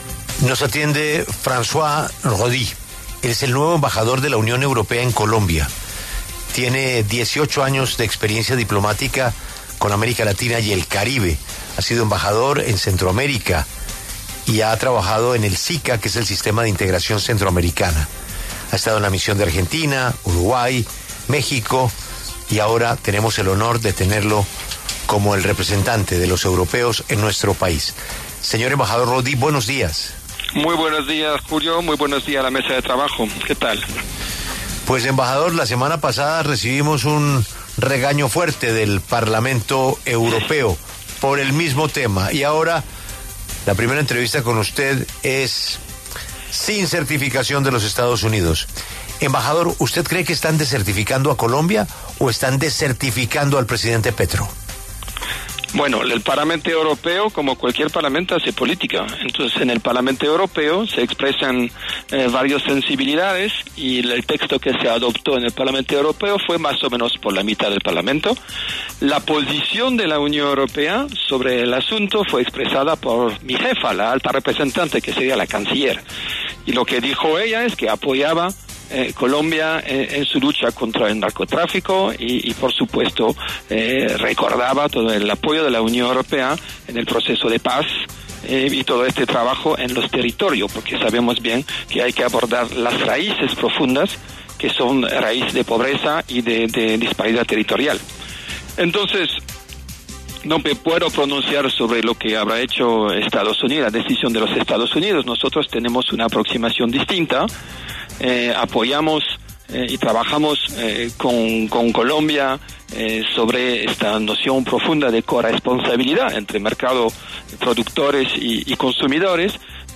En diálogo con Julio Sánchez Cristo para La W, el diplomático francés François Roudié, quien recientemente asumió como embajador de la Unión Europea (UE) en Colombia, se pronunció acerca del fuerte llamado hecho por el Parlamento Europeo para que el país, entre otras cosas, reconozca la existencia del Cartel de los Soles.